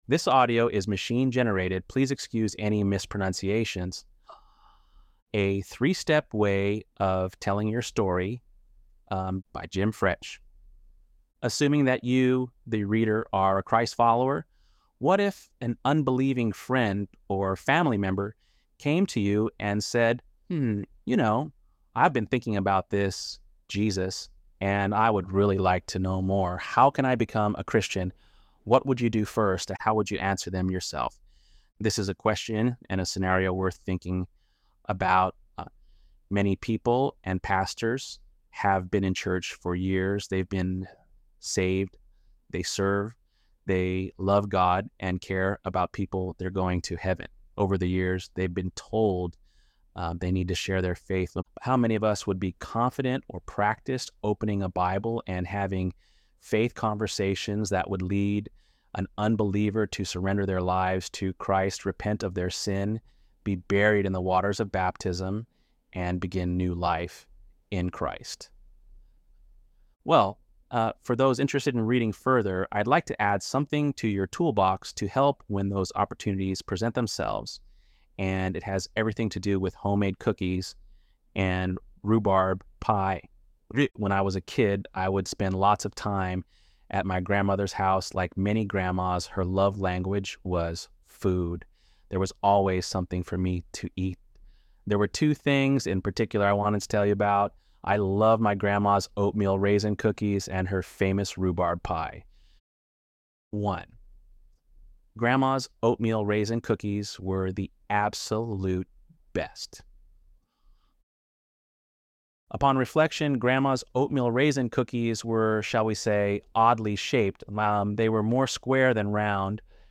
ElevenLabs_3.7_Story.mp3